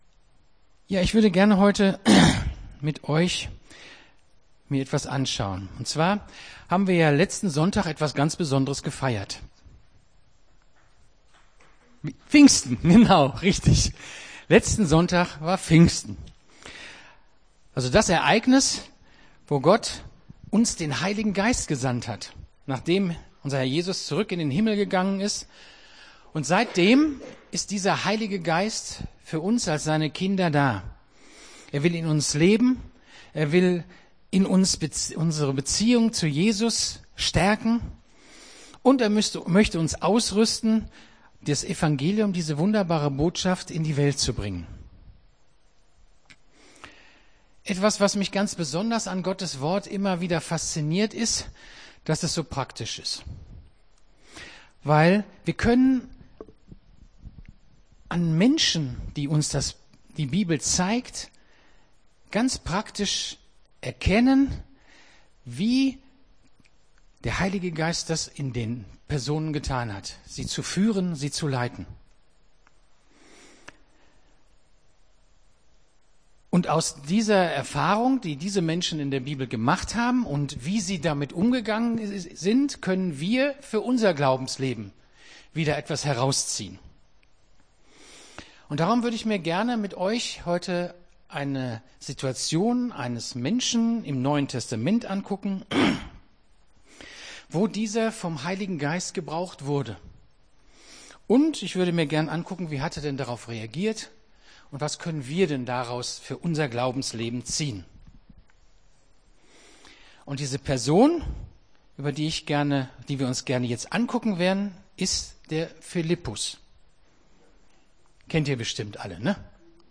Gottesdienst 26.05.24 - FCG Hagen